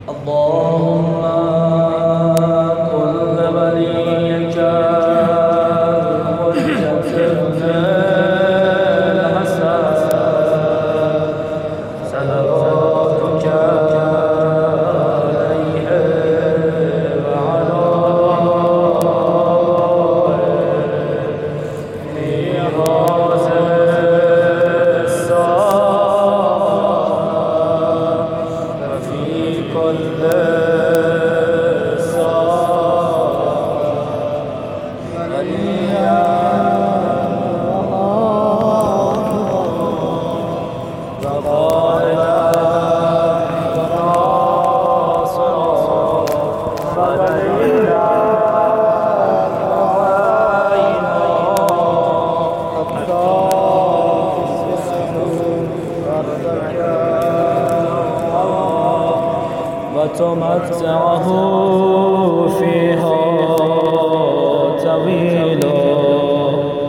مراسم شب عیدغدیر۹۷
عنوان: دعای سلامتی امام زمان(عج)
دعای-سلامتی-امام-زمان.mp3